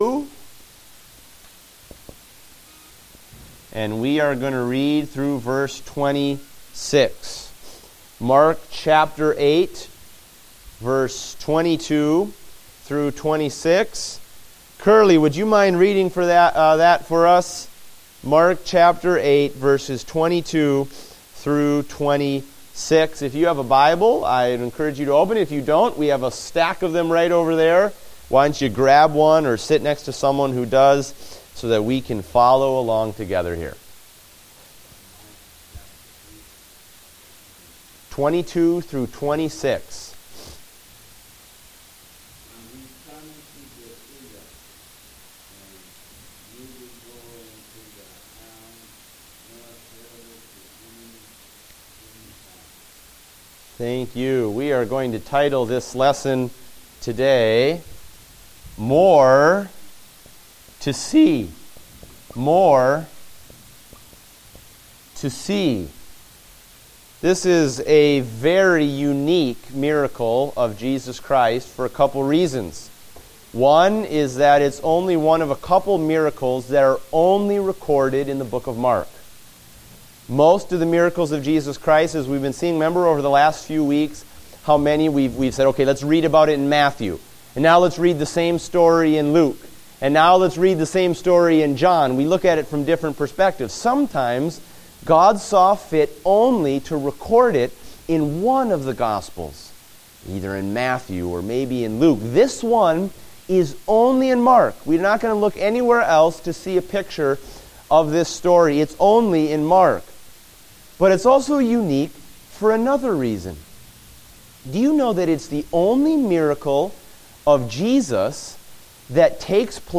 Date: January 3, 2016 (Adult Sunday School)